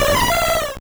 Cri de Cotovol dans Pokémon Or et Argent.